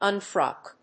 音節un・frock 発音記号・読み方
/`ʌnfrάk(米国英語)/